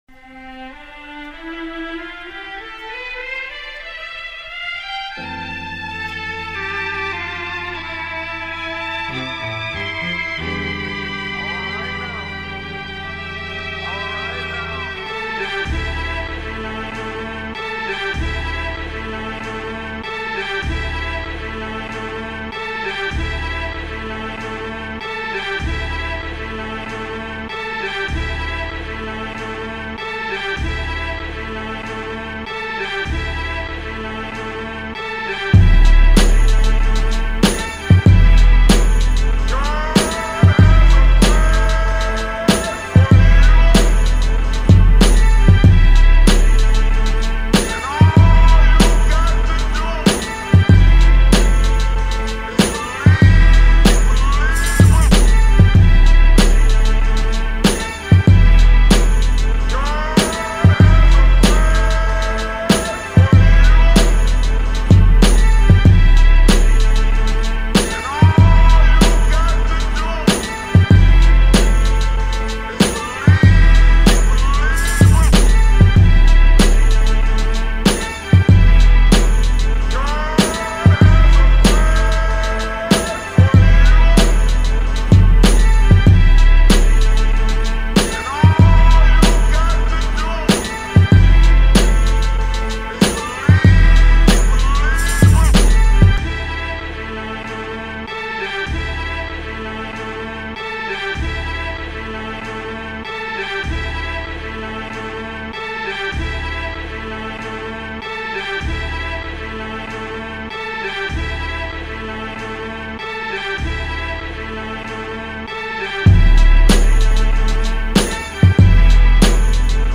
Here's the instrumental version